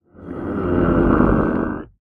Minecraft Version Minecraft Version latest Latest Release | Latest Snapshot latest / assets / minecraft / sounds / mob / warden / agitated_5.ogg Compare With Compare With Latest Release | Latest Snapshot
agitated_5.ogg